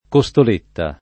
vai all'elenco alfabetico delle voci ingrandisci il carattere 100% rimpicciolisci il carattere stampa invia tramite posta elettronica codividi su Facebook costoletta [ ko S tol % tta ] (meglio che cotoletta ) s. f. (gastron.)